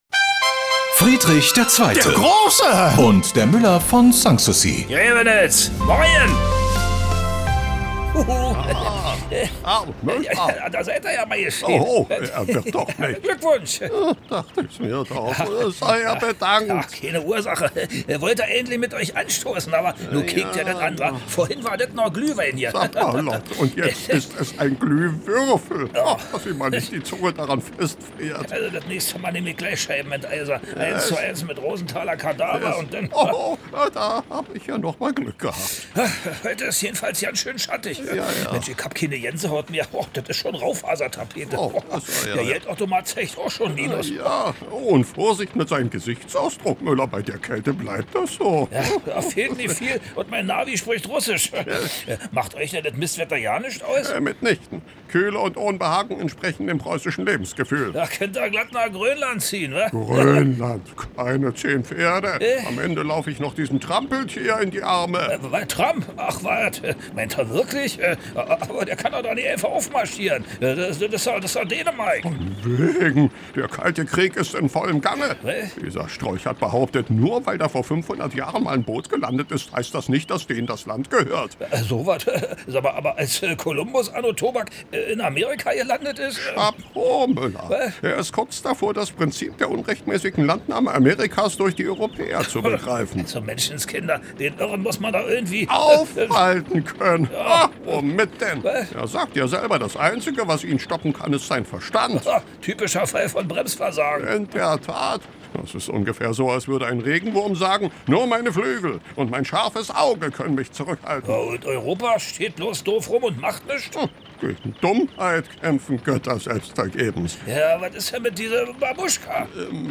Der legendäre Nachbarschaftsstreit setzt sich bis heute fort: Preußenkönig Friedrich II. gegen den Müller von Sanssouci. Immer samstags kriegen sich die beiden bei Antenne Brandenburg in die Haare.
Regionales , Comedy , Radio